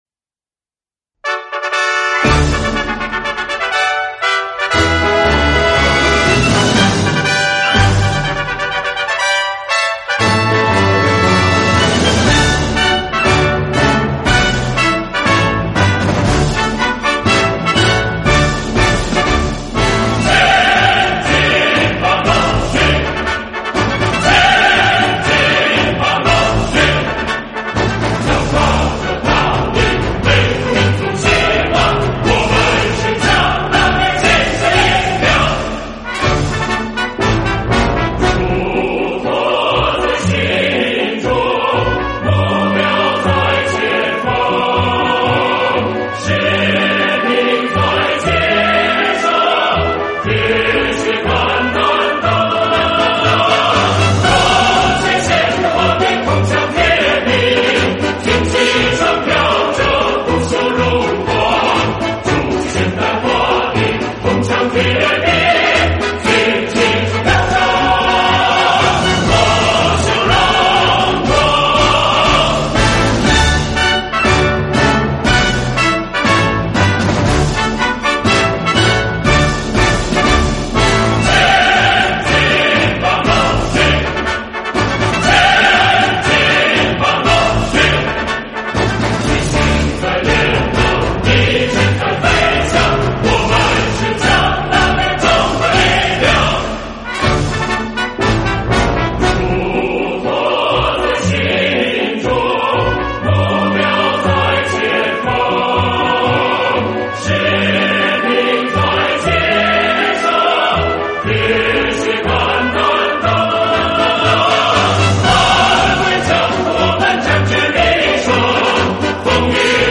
演唱：合唱